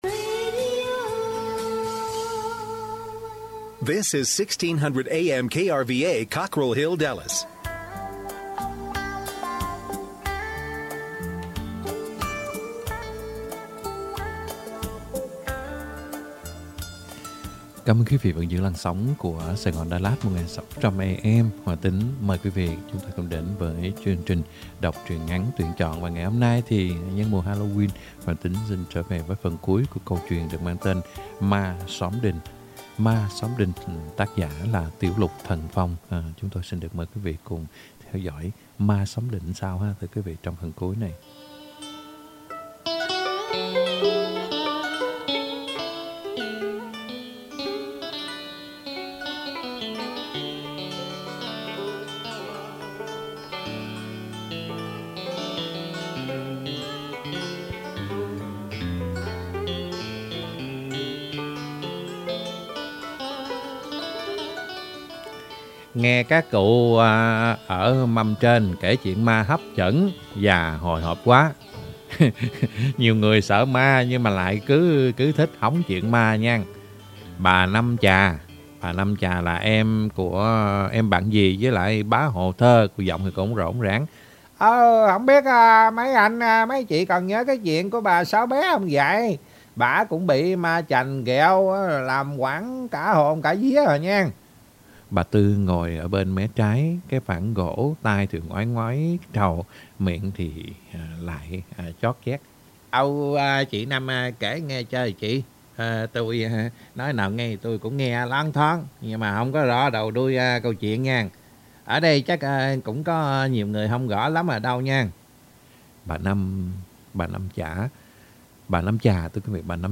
Đọc Truyện Ngắn = Ma Xóm Đình (2 end) - 10/26/2021 .